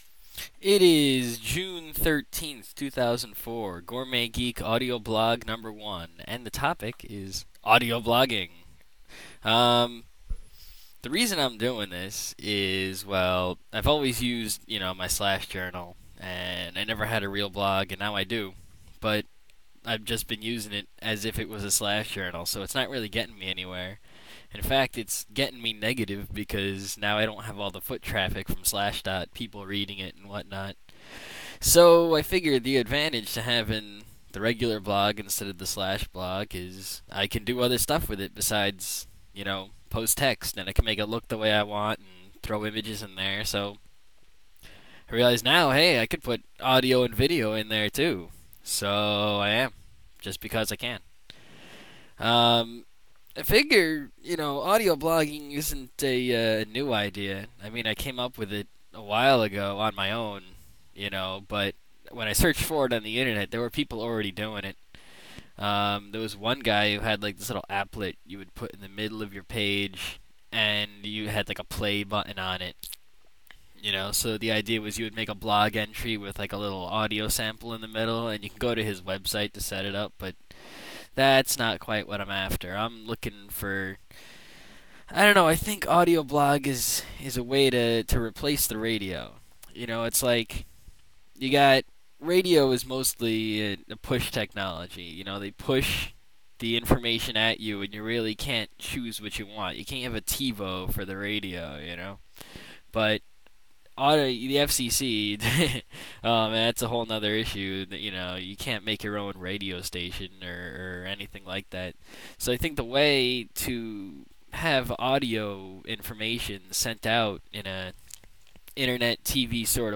Audioblogging
I used rawrec and sox to make the ogg file as that is the simplest way possible.